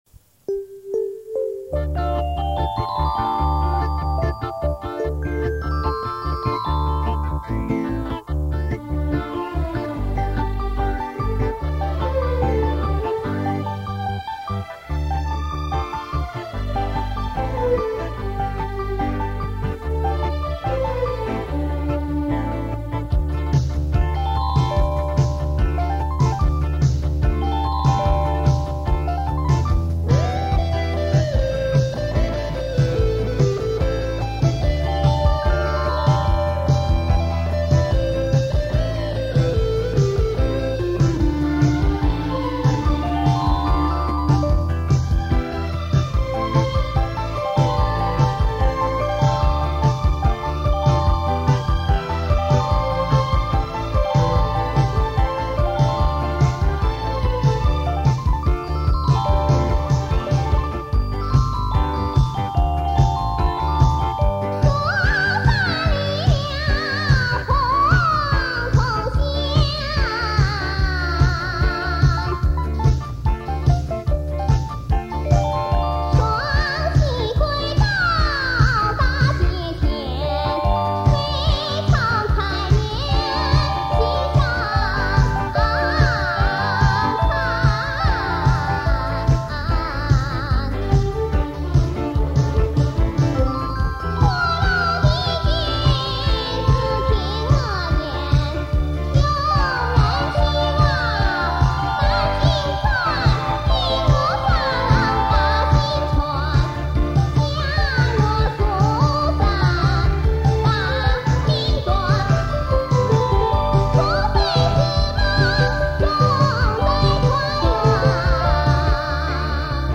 [2/1/2009]【交谊舞曲】好歌伴舞（1）－－中国名曲篇 激动社区，陪你一起慢慢变老！